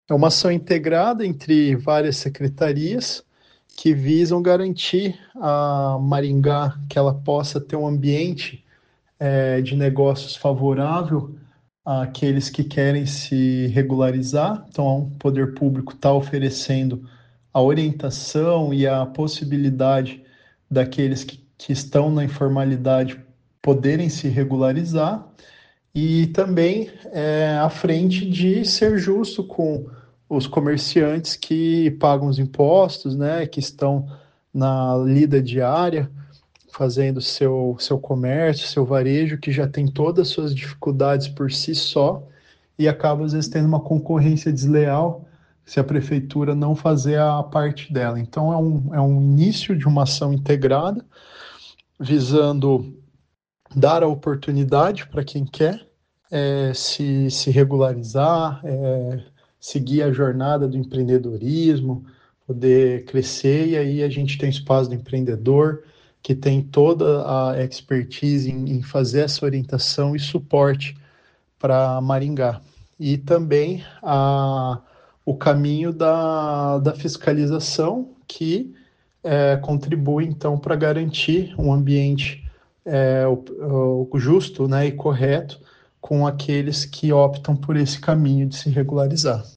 A Prefeitura de Maringá dá início na próxima segunda-feira (2), a uma ação conjunta de orientação e acolhimento voltada aos vendedores ambulantes que atuam na cidade sem o devido licenciamento. A iniciativa busca incentivar a regularização e organizar o comércio ambulante. Ouça o que diz o secretário de Aceleração Econômica, Annibal Bianchini.